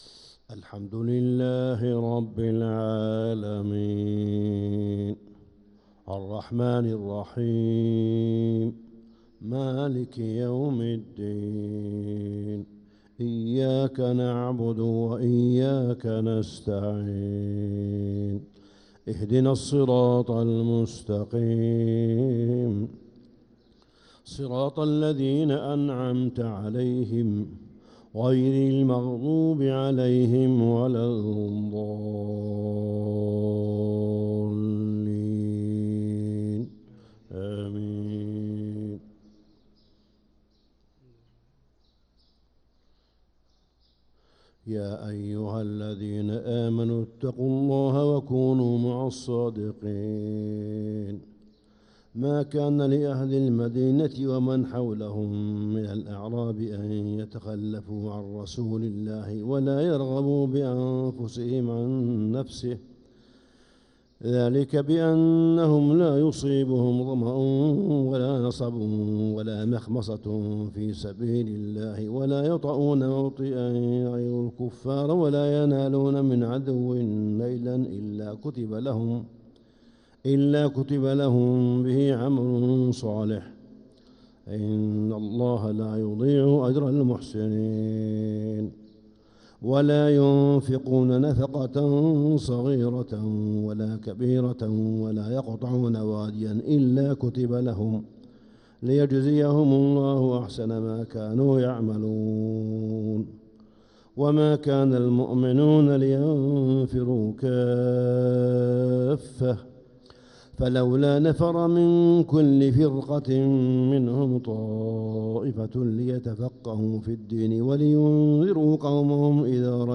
فجر الخميس 6-9-1446هـ خواتيم سورة التوبة 119-129 | Fajr prayer from Surat at-Taubah 6-3-2025 > 1446 🕋 > الفروض - تلاوات الحرمين